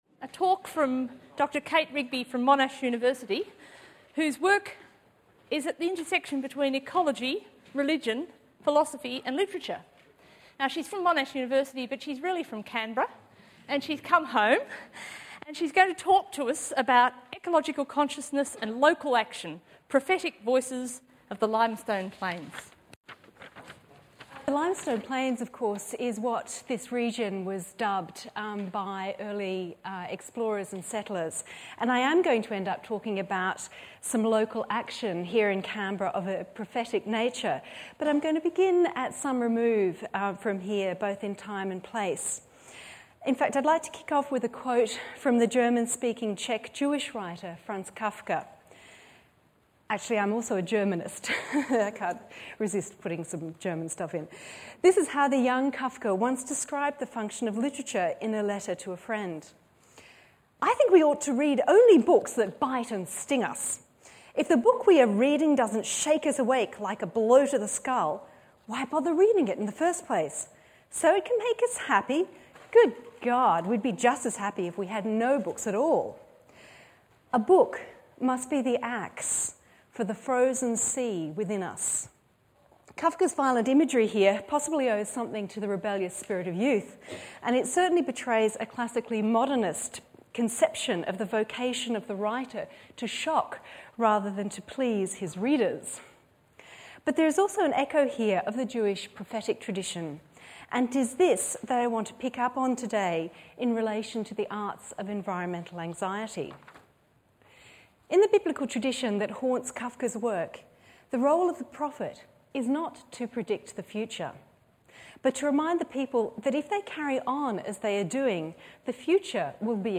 Violent Ends: The Arts of Environmental Anxiety 01 Jan 2018 Ecological Consciousness and Local Action Recording includes a reading of Judith Wright's Dust from Collected Poems with permission from HarperCollins.